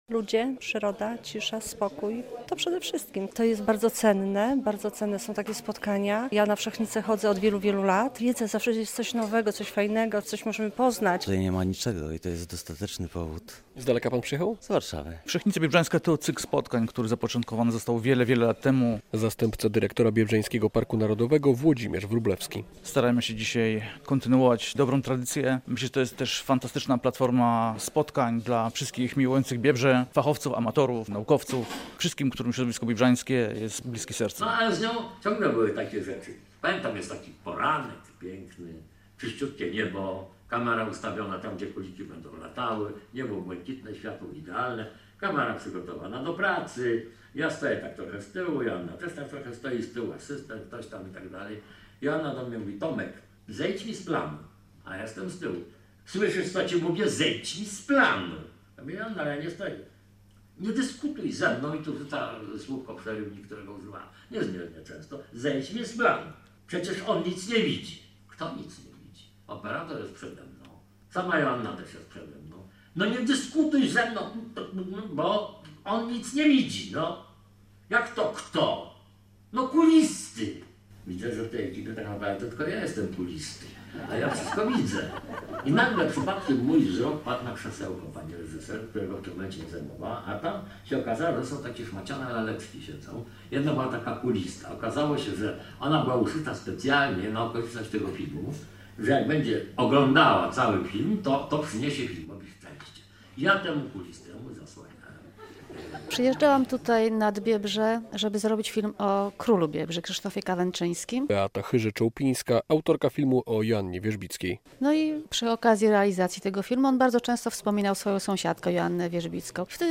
Spotkania z przyrodnikami, wykłady i wycieczka terenowa. W siedzibie Biebrzańskiego Parku Narodowego w Osowcu zaczyna się 81. Wszechnica Biebrzańska.